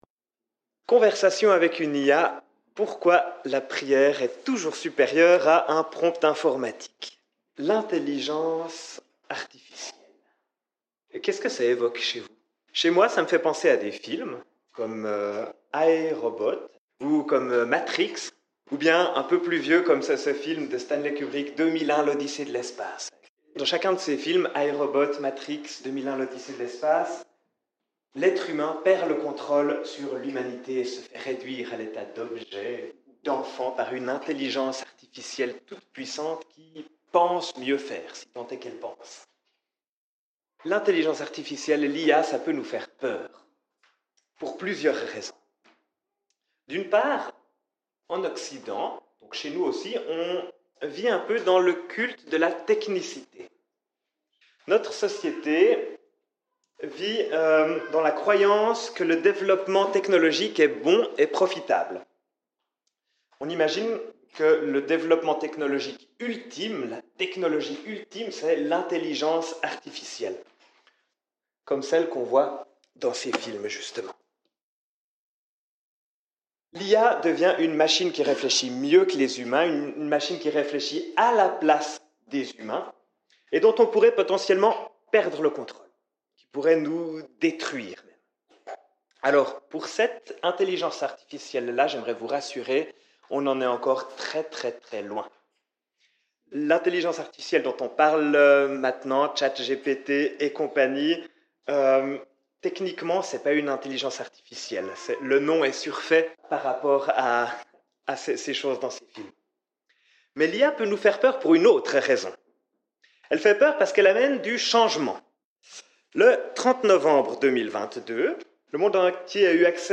Dans cette nouvelle série, nous suivrons son chemin, depuis son appel jusqu’à la naissance d’Ismaël. Viens vivre un culte joyeux et accessible à tous, où les enfants sont plus que bienvenus !